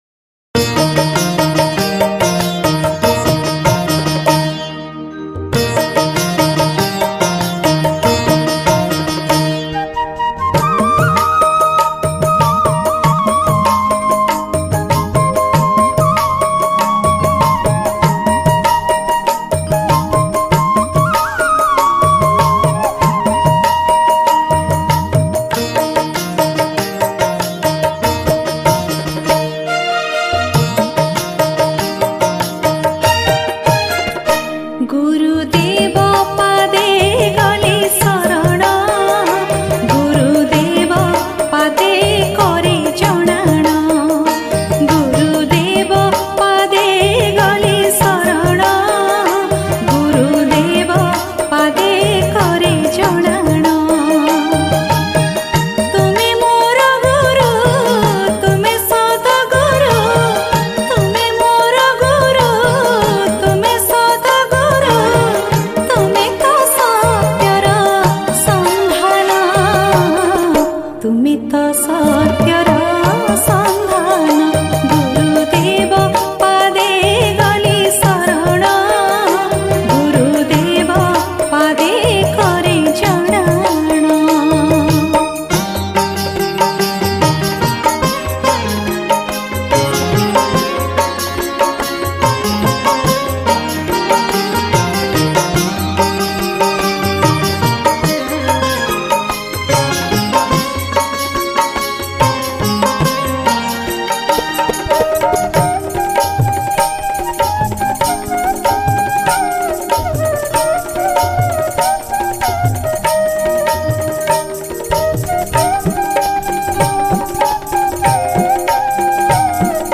Odia Bhajan